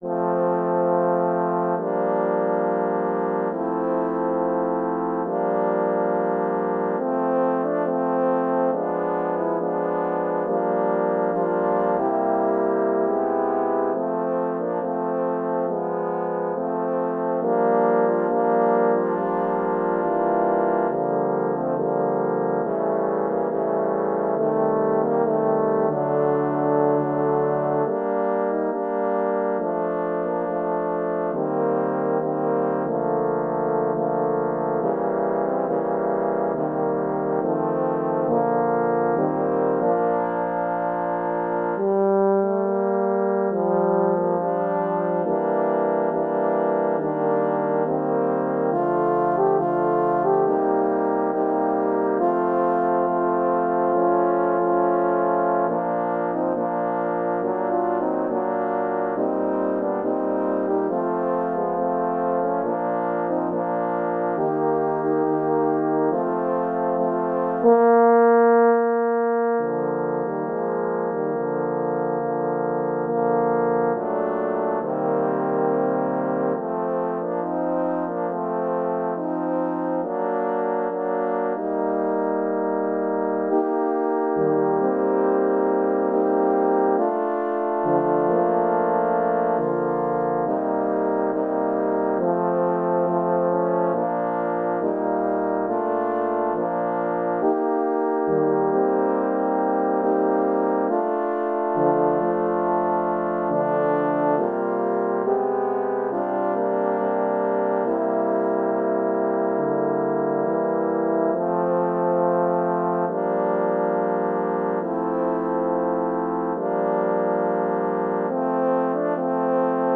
Pour 5 cors en fa (ou multiple)